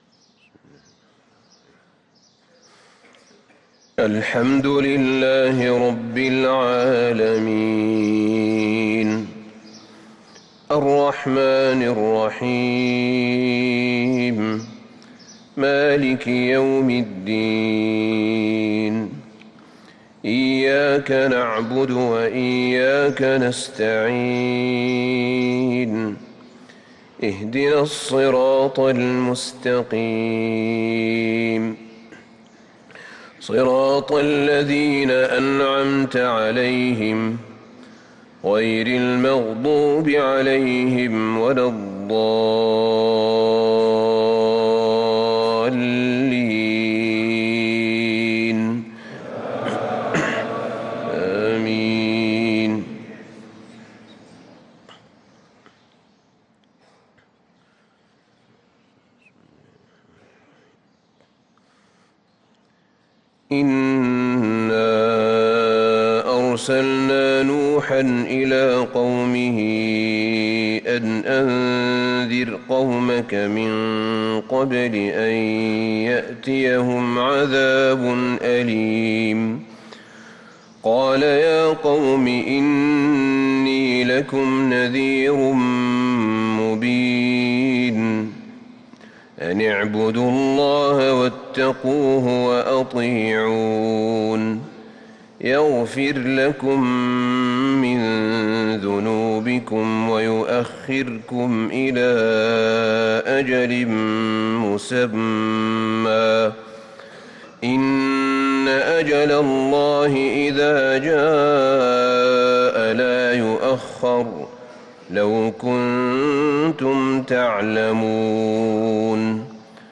صلاة الفجر للقارئ أحمد بن طالب حميد 28 ربيع الآخر 1445 هـ
تِلَاوَات الْحَرَمَيْن .